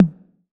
6TOM HI 2.wav